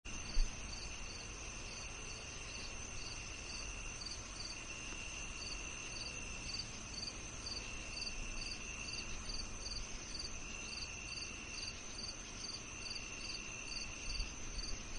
Night Crickets
Night Crickets is a free nature sound effect available for download in MP3 format.
# insects # night # loop About this sound Night Crickets is a free nature sound effect available for download in MP3 format.
009_night_crickets.mp3